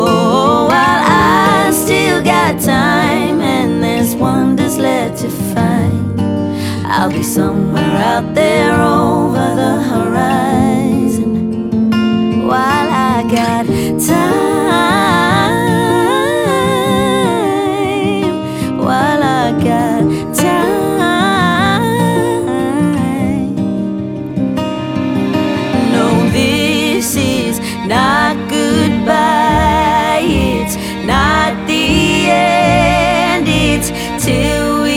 Pop Singer Songwriter
Жанр: Поп музыка